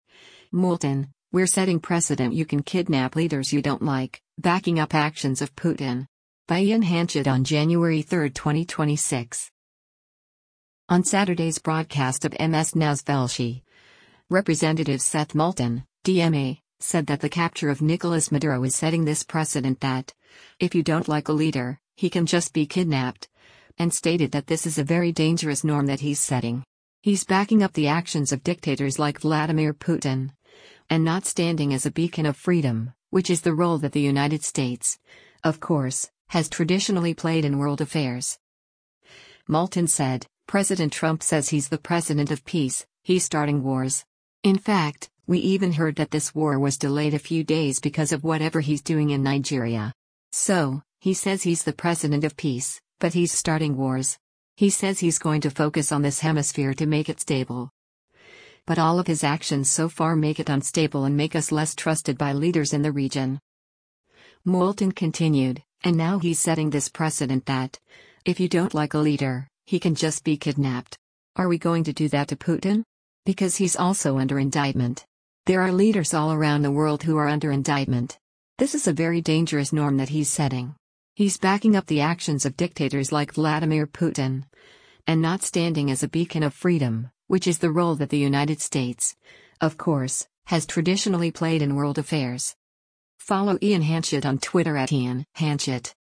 On Saturday’s broadcast of MS NOW’s “Velshi,” Rep. Seth Moulton (D-MA) said that the capture of Nicolas Maduro is “setting this precedent that, if you don’t like a leader, he can just be kidnapped.”